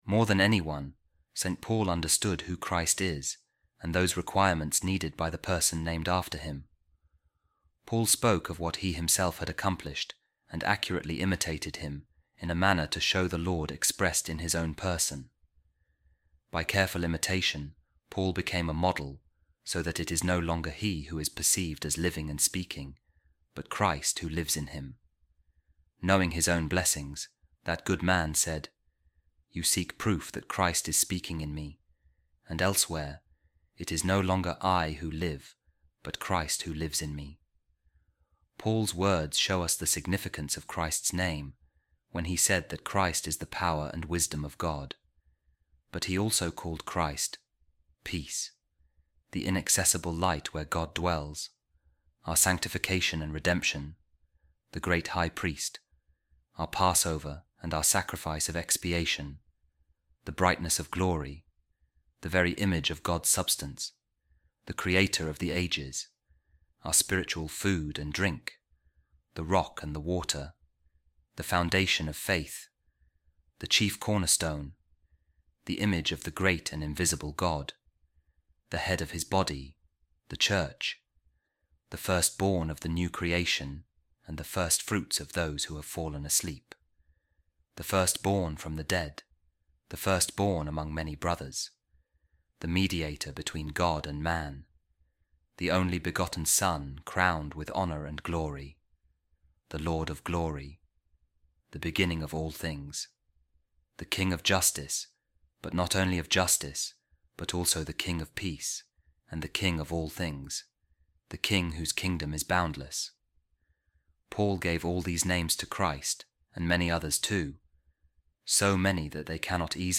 A Reading From The Treatise Of Saint Gregory Of Nyssa On Christian Perfection | The Christian Is Another Jesus Christ